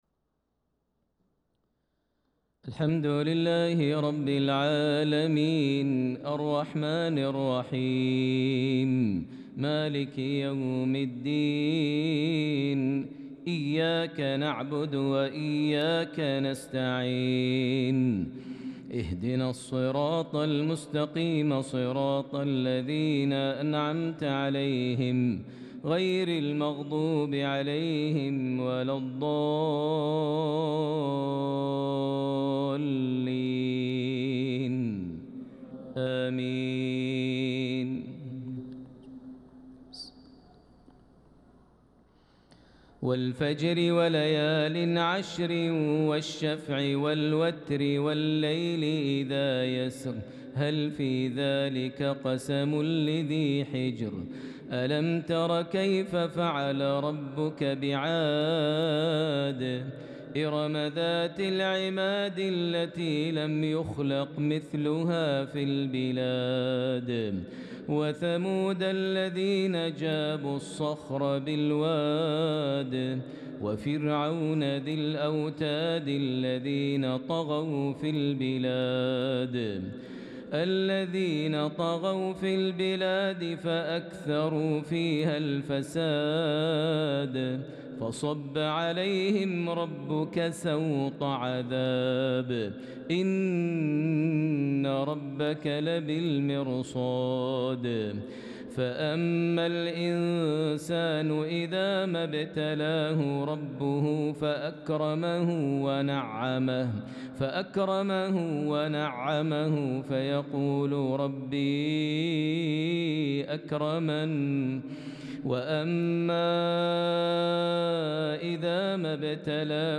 صلاة العشاء للقارئ ماهر المعيقلي 26 ذو الحجة 1445 هـ
تِلَاوَات الْحَرَمَيْن .